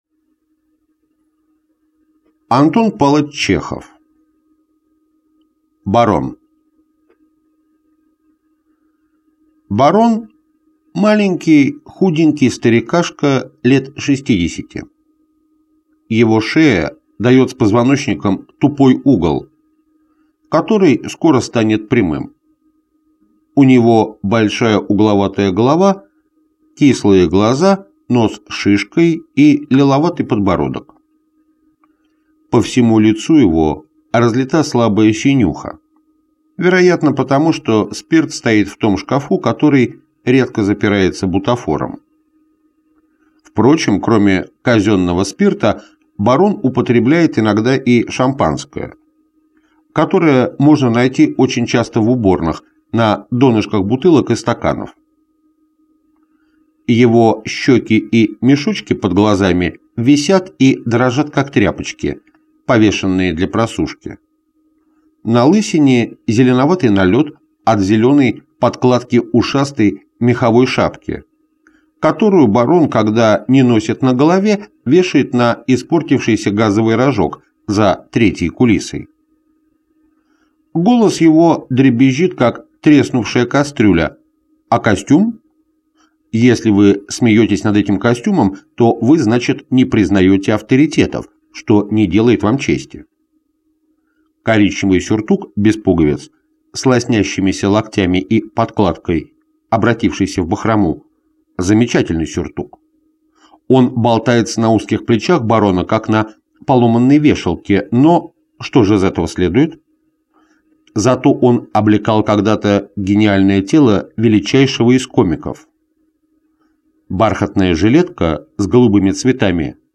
Аудиокнига Рассказы, юморески 1880 – 1882 г.г. Том 2 | Библиотека аудиокниг